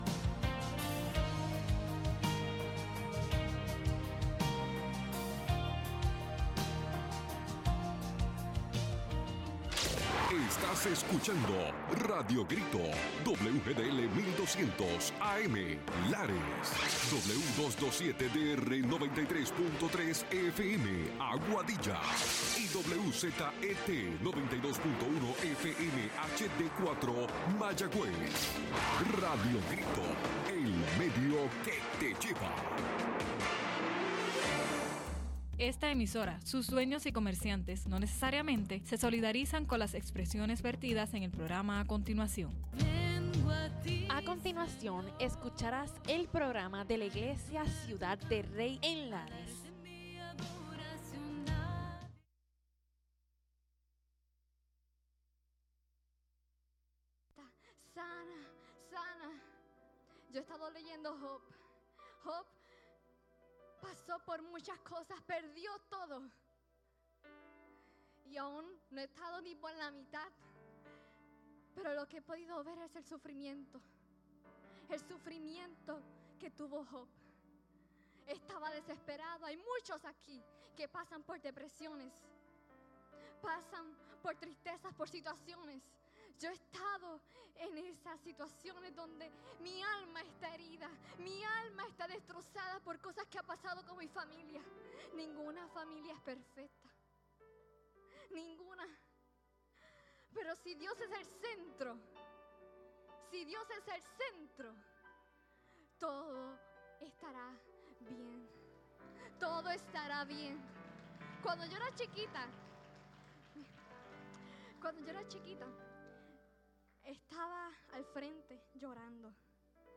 Los hermanos de Ciudad del Rey nos traen un programa especial de su servicio en la iglesia.